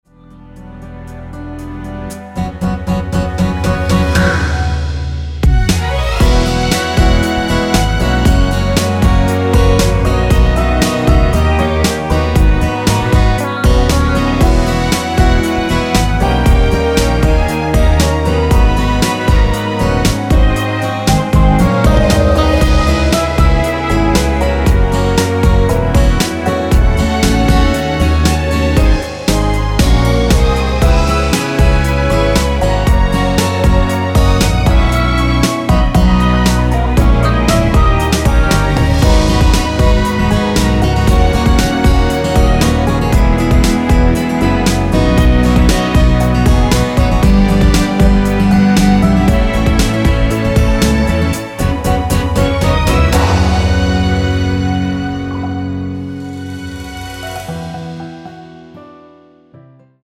원키에서(-1)내린 (1절+후렴)으로 진행되는 MR입니다.
Abm
앞부분30초, 뒷부분30초씩 편집해서 올려 드리고 있습니다.
중간에 음이 끈어지고 다시 나오는 이유는